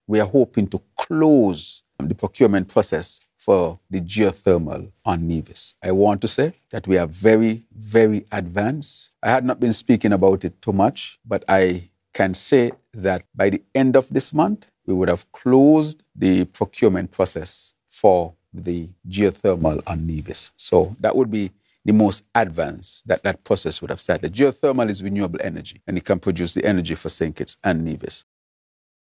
Prime Minister, Dr. Terrance Drew, gave this update on the project, Feb. 17th.